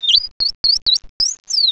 sound / direct_sound_samples / cries / shaymin.aif